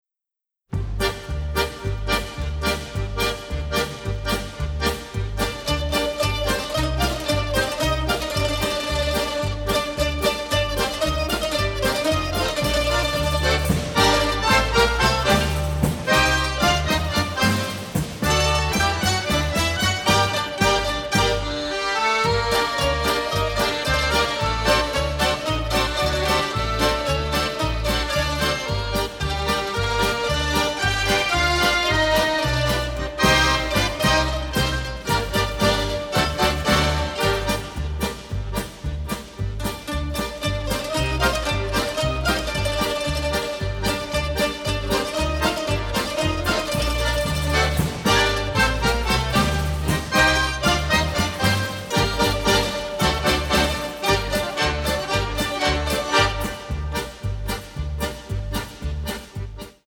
The Soundtrack Album (stereo)